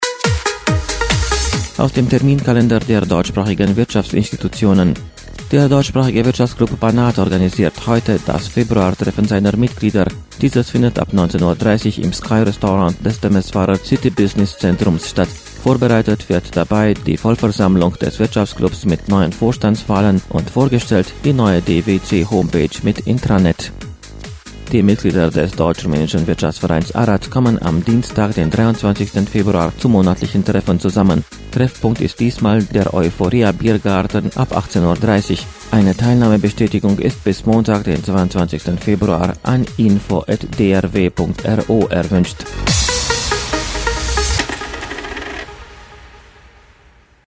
Sie hören das Wirtschaftsmagazin bei Radio Temeswar, jetzt mit den Wirtschaftsmeldungen dieser Woche: